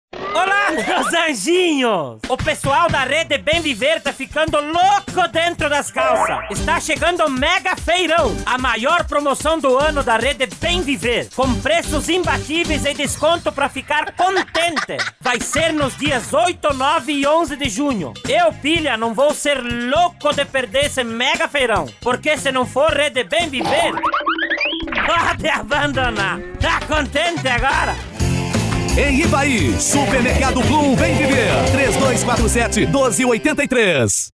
Spot Institucional